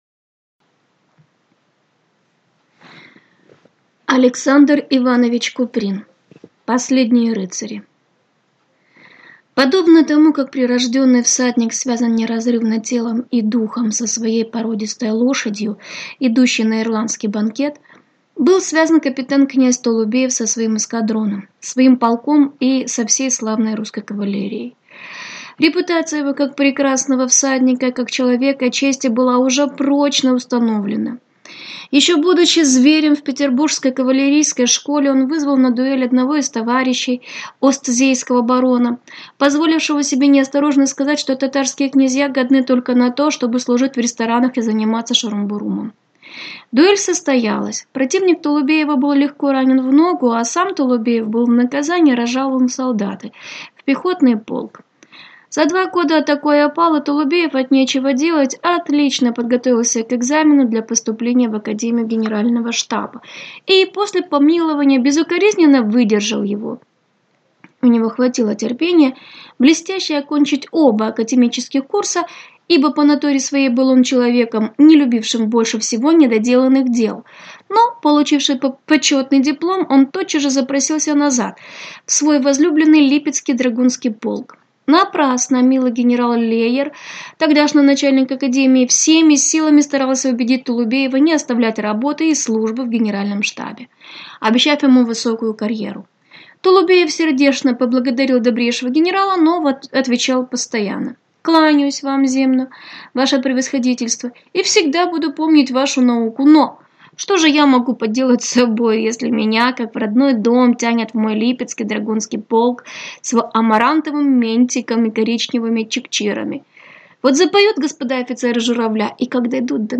Аудиокнига Последние рыцари | Библиотека аудиокниг